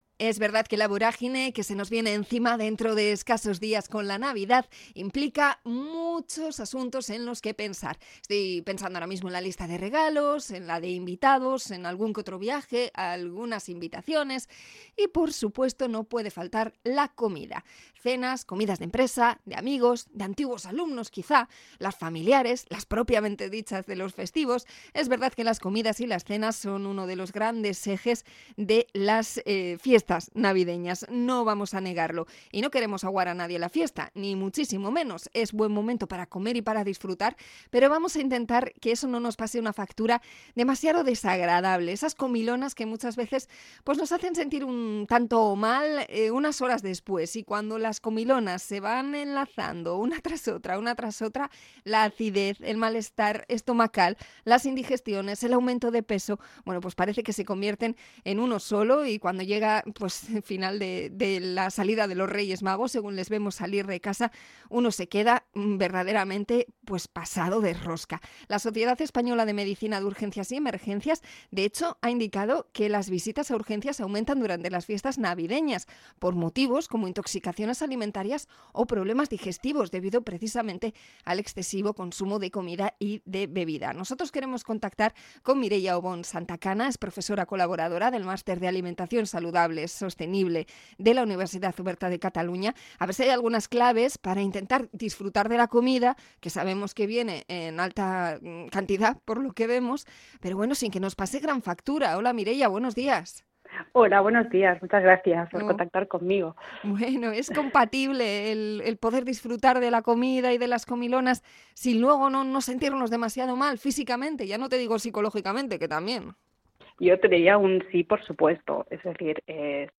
Entrevista a nutricionista por los atracones navideños